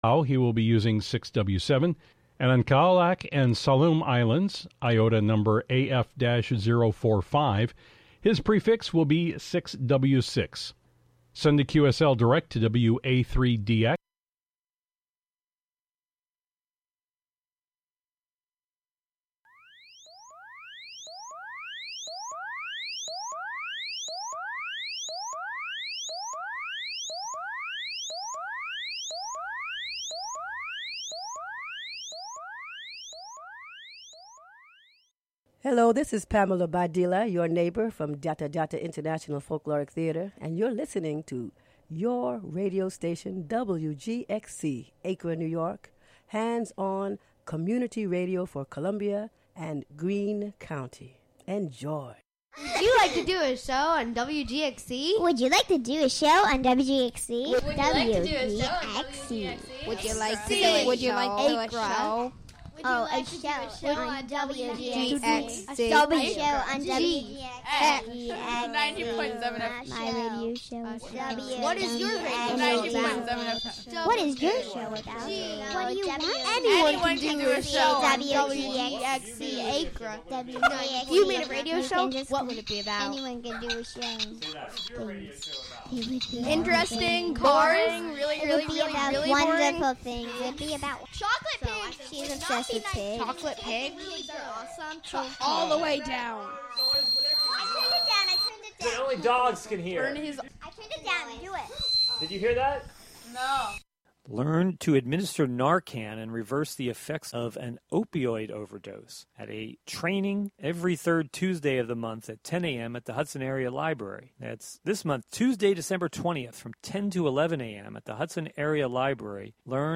Contributions from many WGXC programmers.